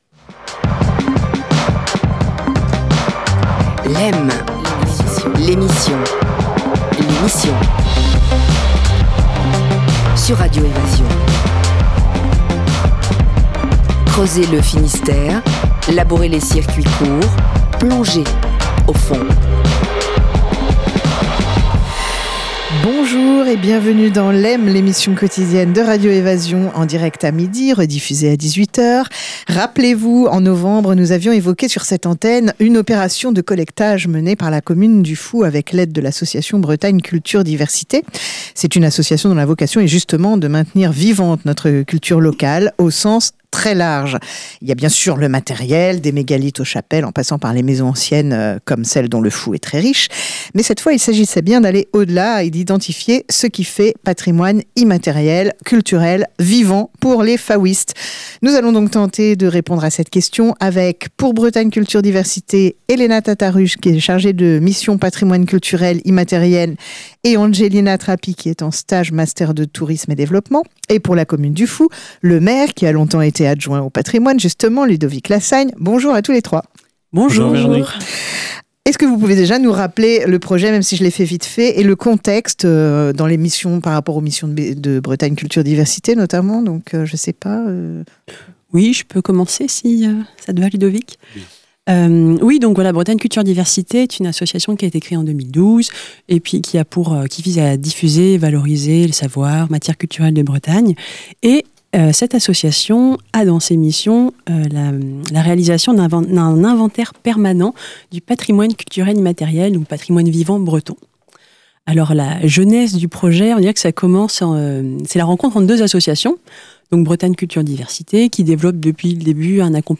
Réécoutez l'émission